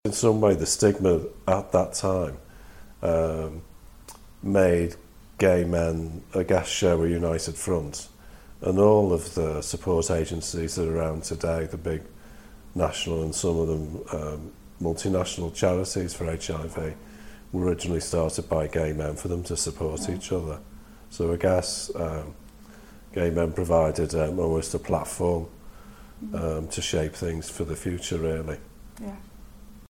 Edited Interview